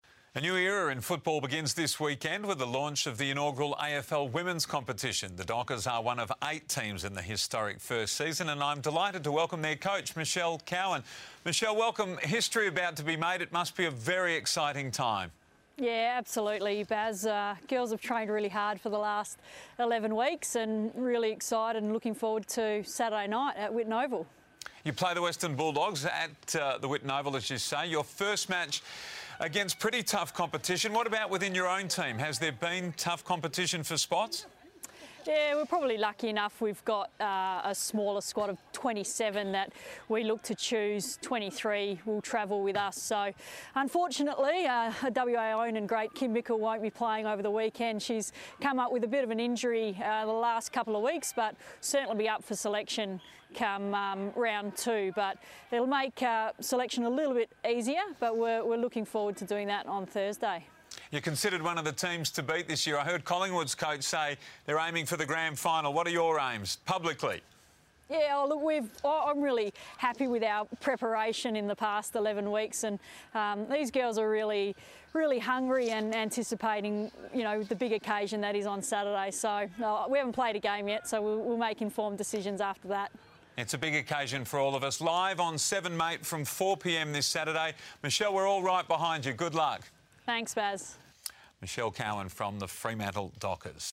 spoke to Seven news for her first live cross of the season.